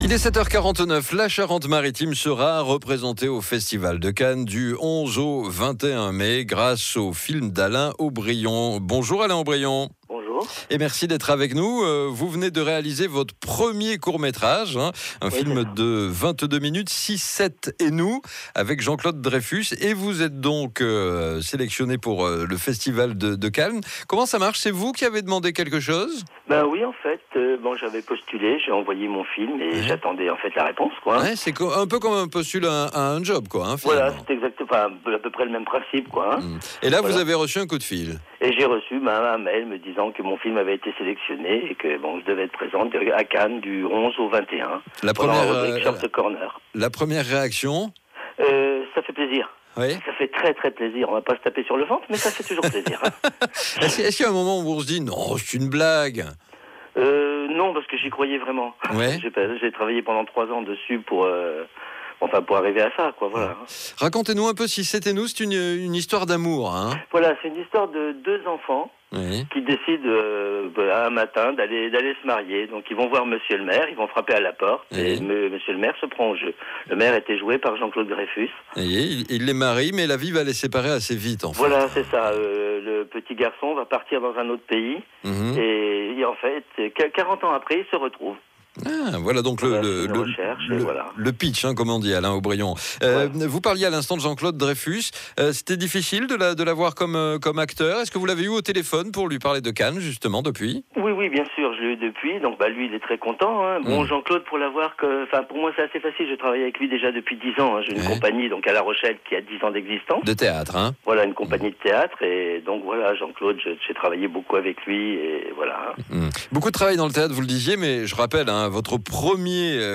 Interview radio france.mp3